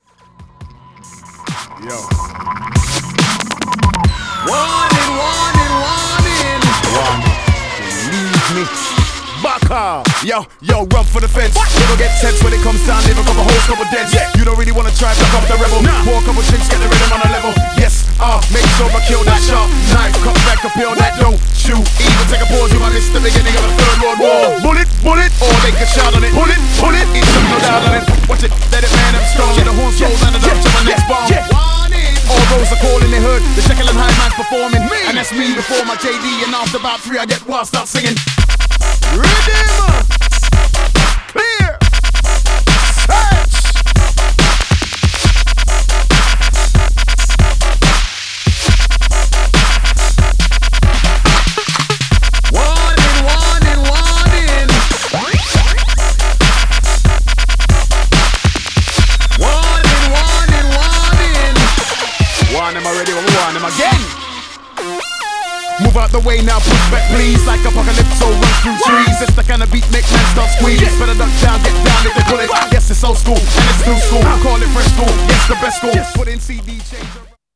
Styl: Drum'n'bass Vyd�no